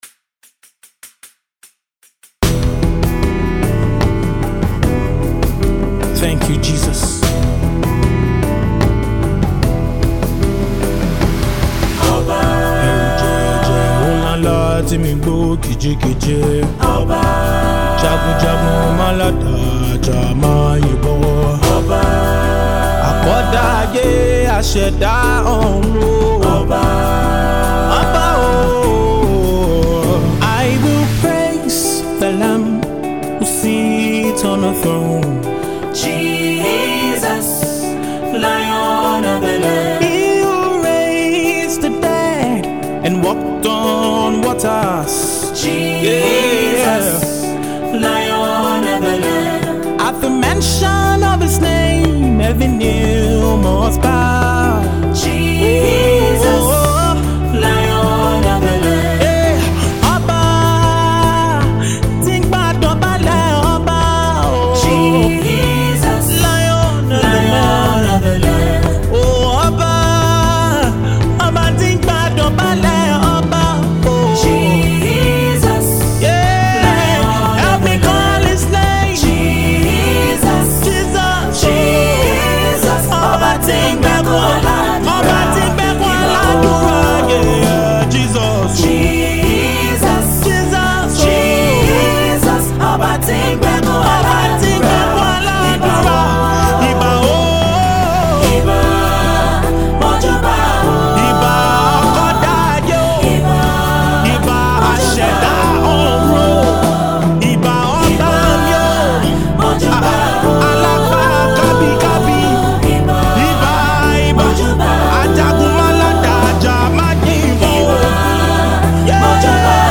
majestic worship anthem